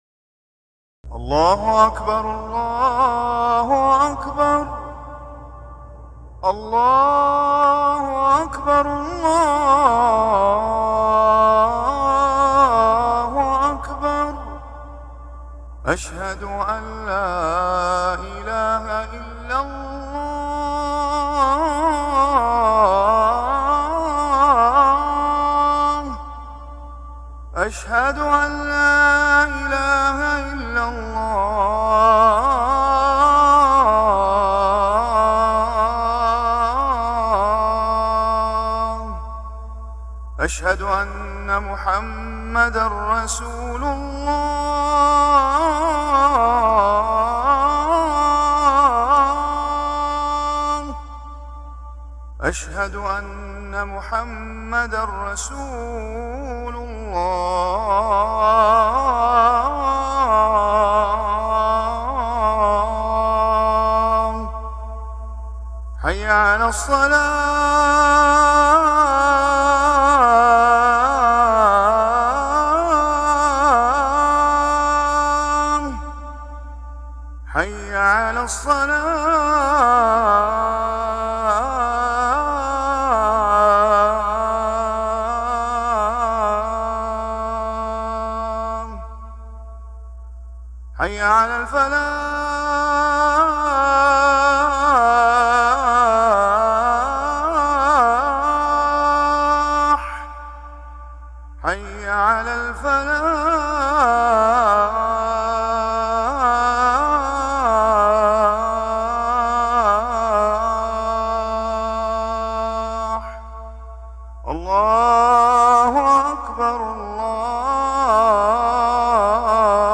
أذان الجمعة الثاني للمؤذن